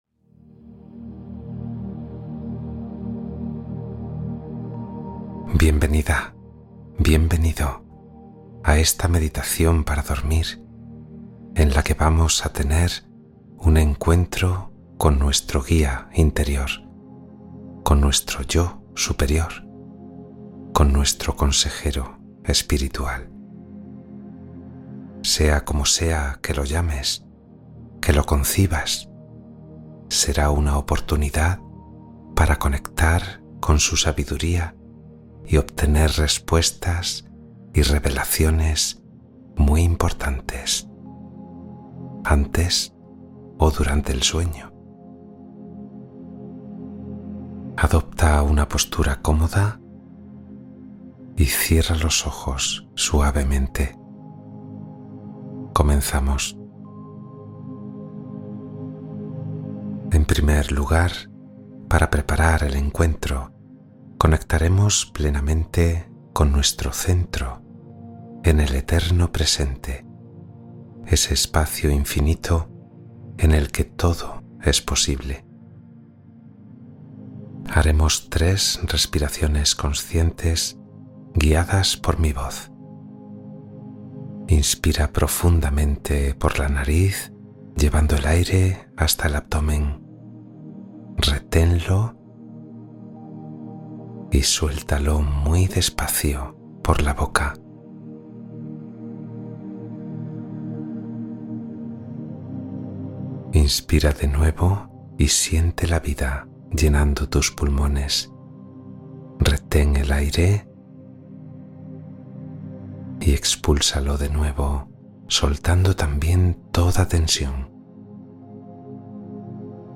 Meditación para dormir conectando con tu guía interior de sabiduría